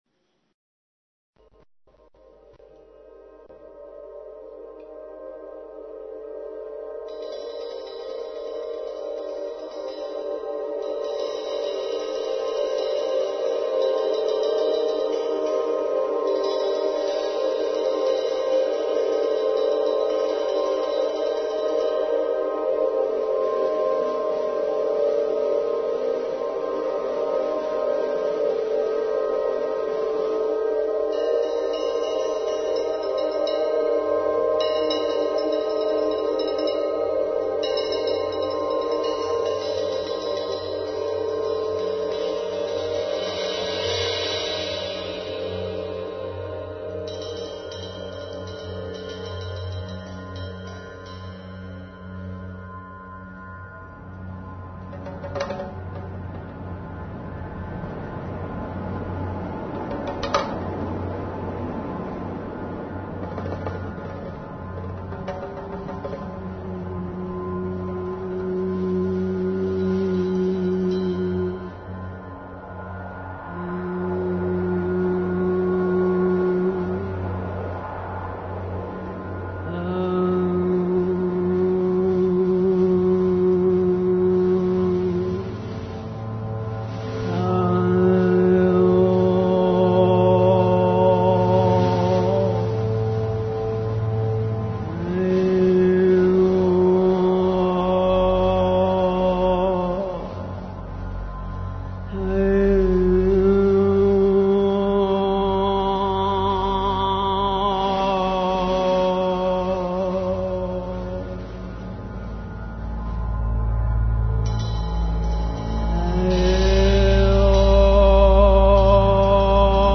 вокал
ударные